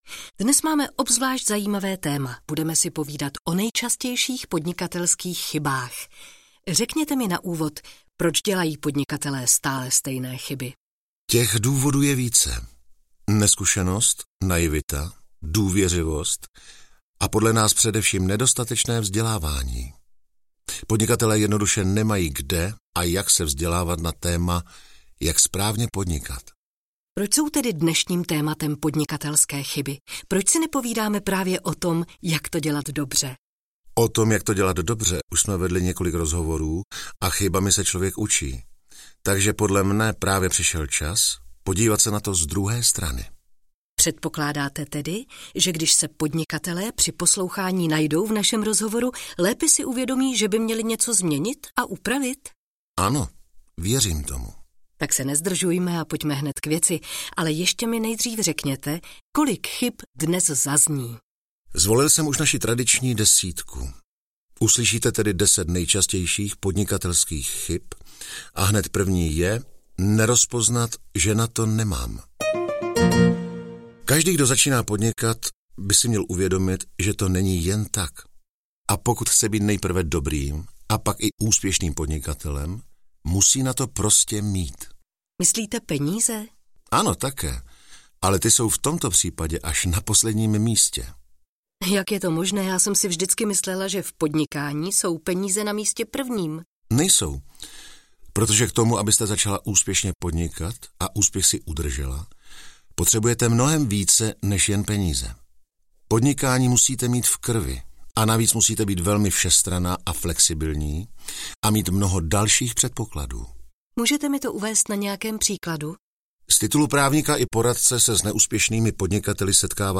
Audio knihaDeset nejčastějších podnikatelských chyb
Ukázka z knihy